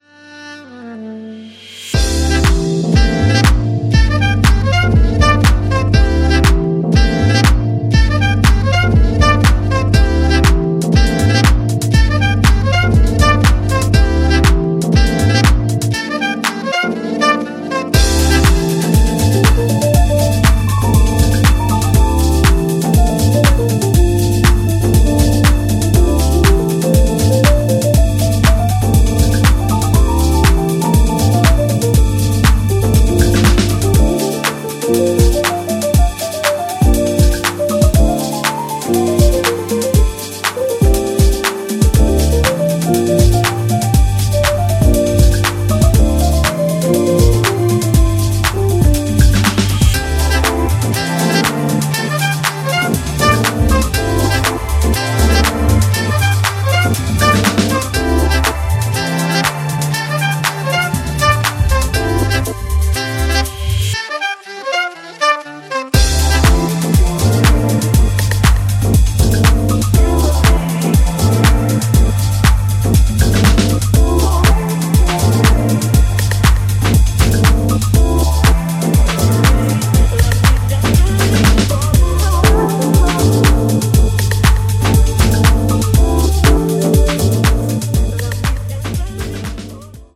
ヒップホップ、ジャズ、ソウル、ファンク等のエッセンスを感じさせつつ、いつになく多岐に渡るアウトプットです。
ジャンル(スタイル) BROKEN BEAT / JUNGLE / HOUSE / HIP HOP / JAZZ